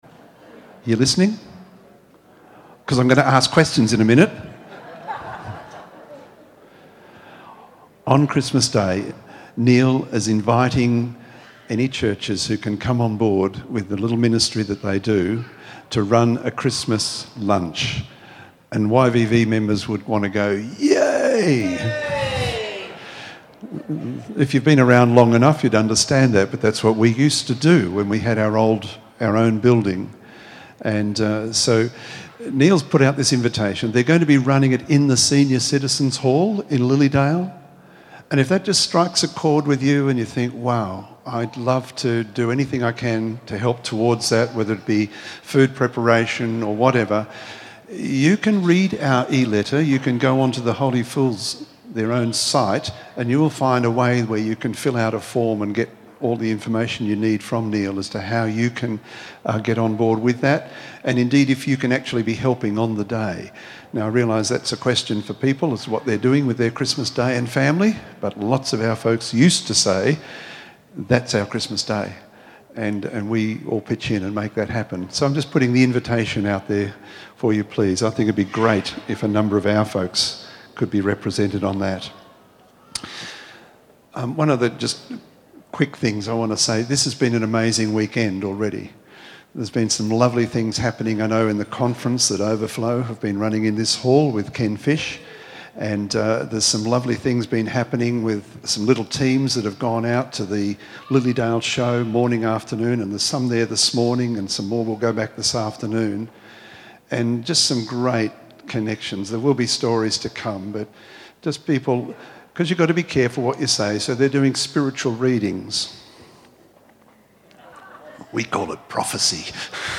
Here's a message I preached at a Vineyard church in Victoria during their pastoral search...